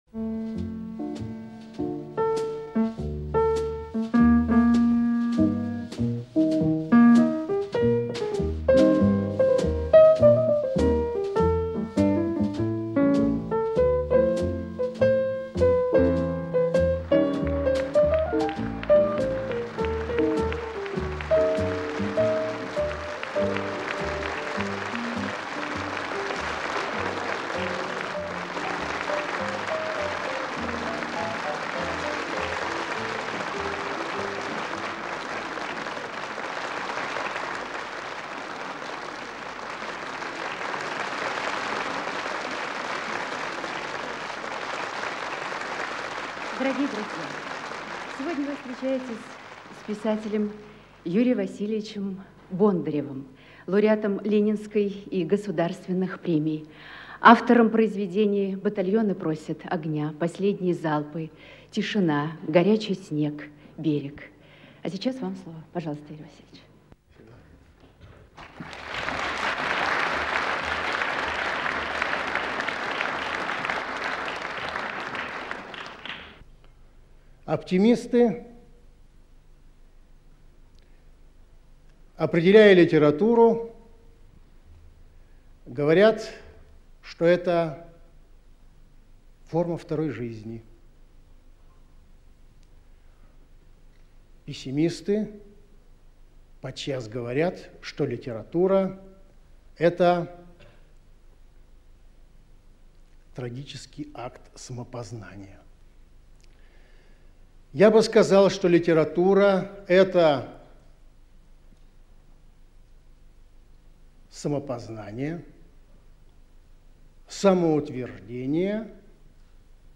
Приглашаем вас на встречу с замечательным писателем, к глубокому и интересному разговору о предназначении литературы, о вечных ценностях и таких важных понятиях, как красота, доброта, гармония, совесть, долг, счастье, дружба и любовь. Юрий Бондарев рассказывает о том, как рождались замыслы его произведений, звучат рассказы "Миг" и "Почему я пожал ему руку" из сборника "Мгновения" в авторском исполнении.